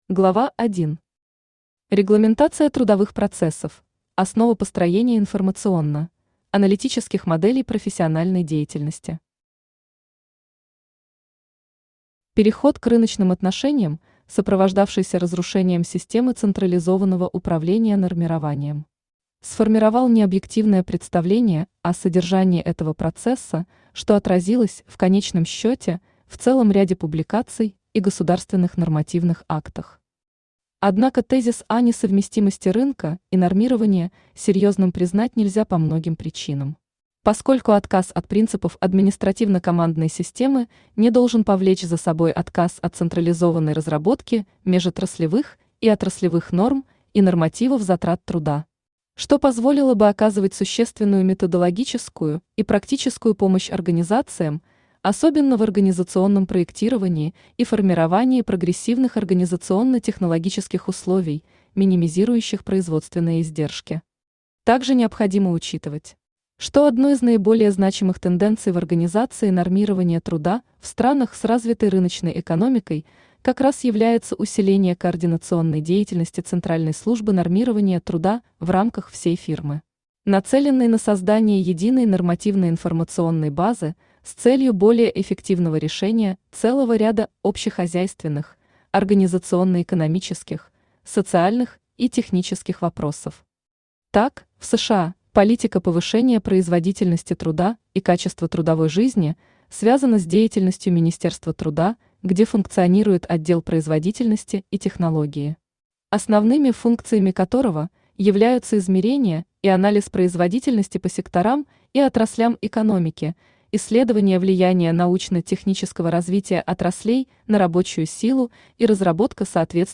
Aудиокнига Информационно – аналитические модели организации трудовых процессов Автор Елена Александровна Величко Читает аудиокнигу Авточтец ЛитРес.